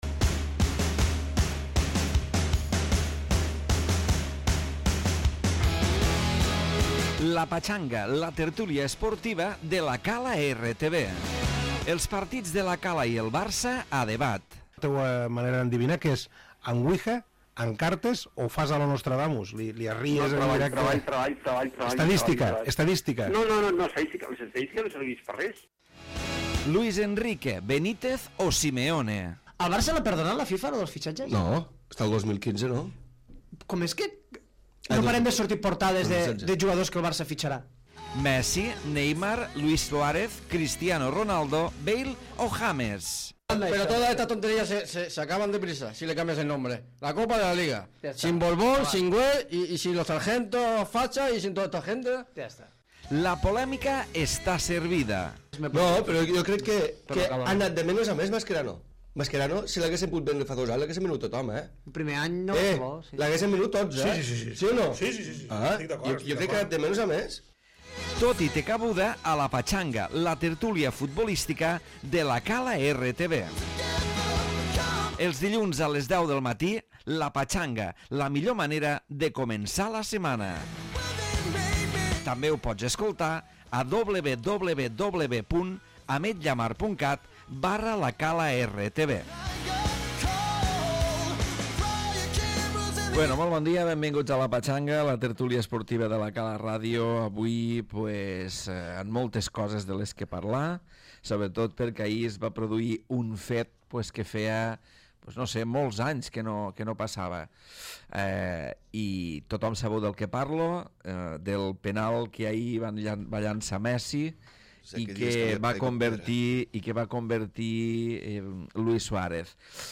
Tertúlia esportiva amb l'actualitat de La Cala, el Barça i el Madrid. Avui atenció especial al penalt de Messi i Luís Suárez.